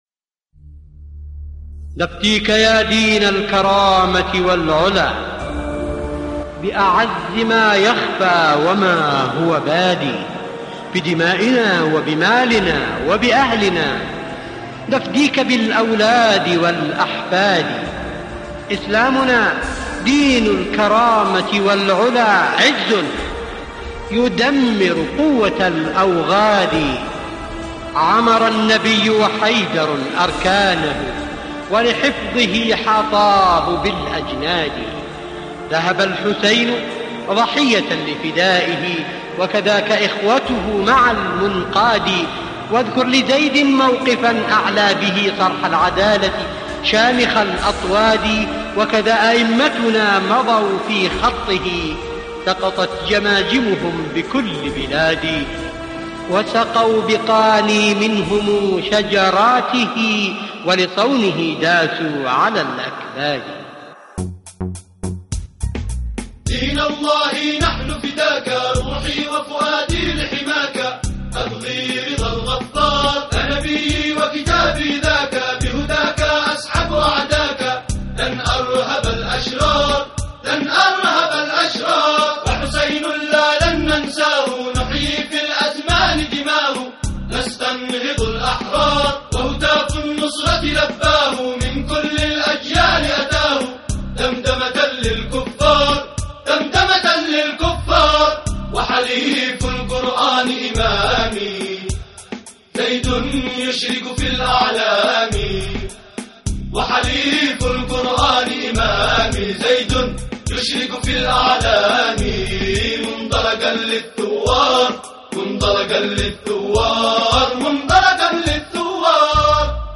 اناشيد يمنية